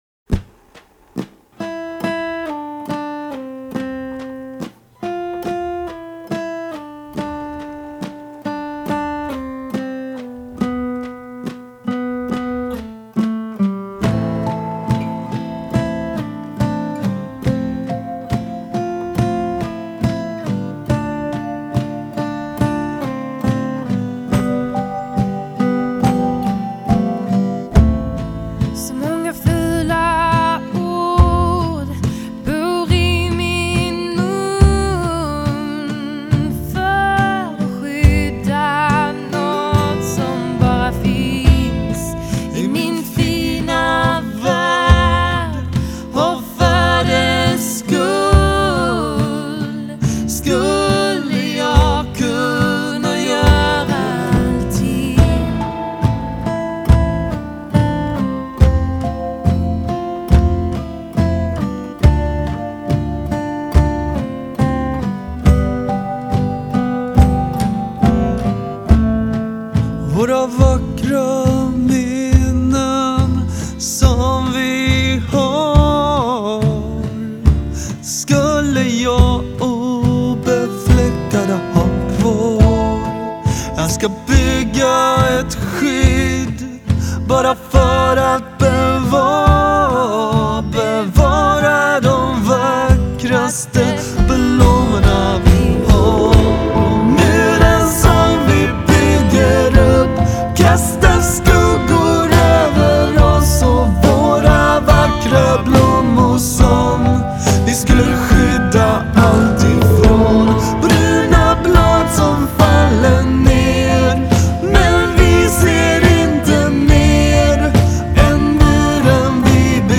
LEADSÅNG, GITARR, PERCUSSION
BAS, KÖR, SYNTHESIZER
LEADGITARR, KÖR
TRUMMOR, KÖR